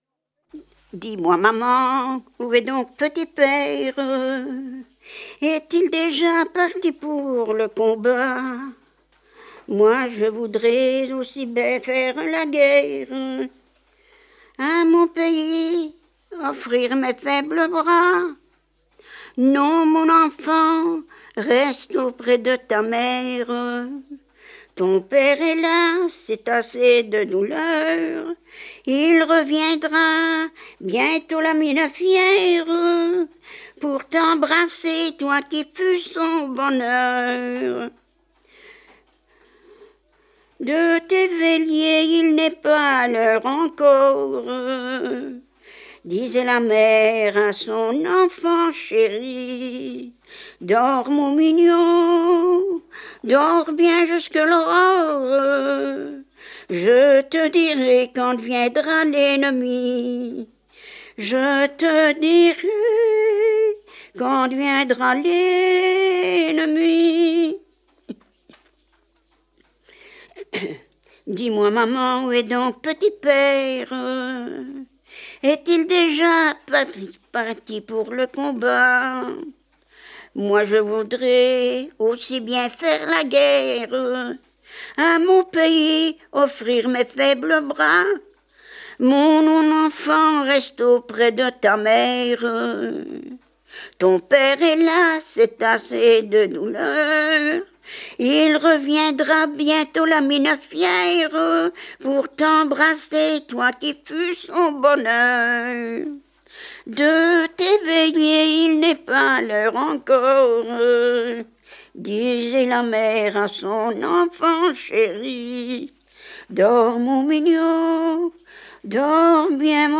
Genre : chant
Type : chanson narrative ou de divertissement
Lieu d'enregistrement : Vierves-sur-Viroin
Support : bande magnétique
Chanson incomplète : l'interprète chante le 2e couplet et un refrain.